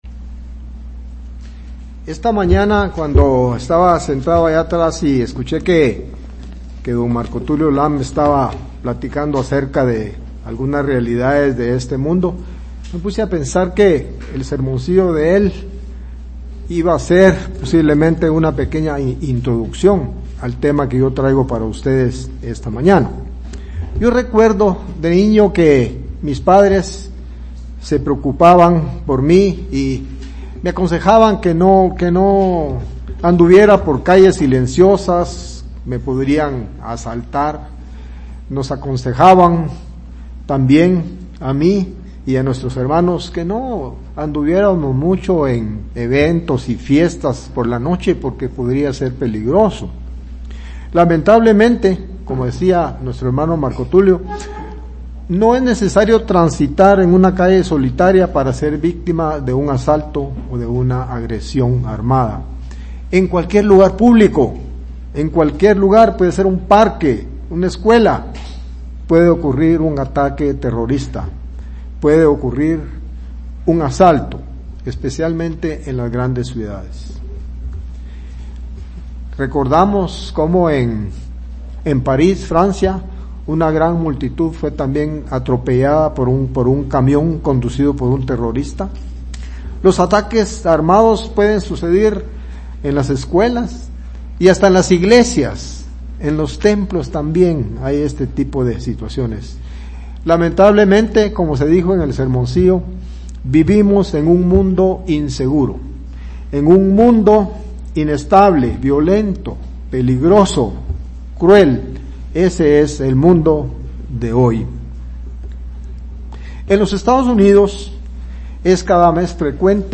Sermones
Given in Ciudad de Guatemala